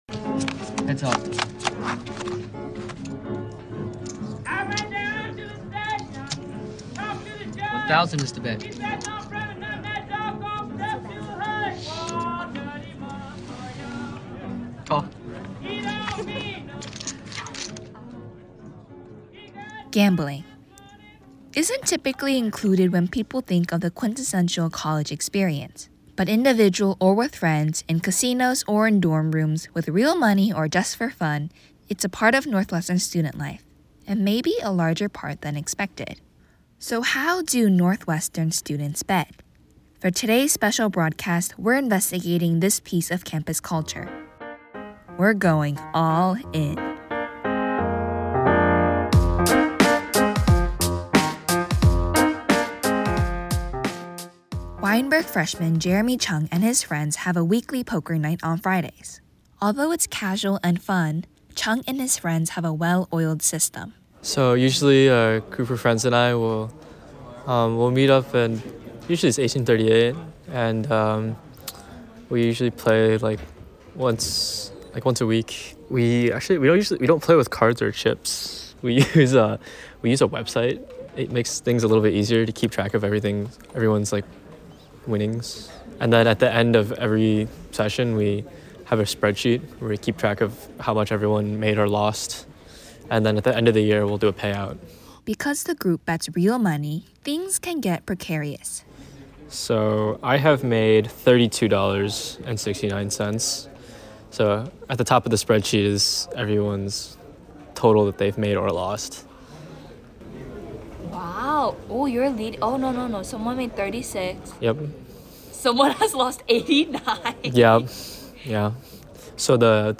This story originally aired as part of our A.I. Special Broadcast.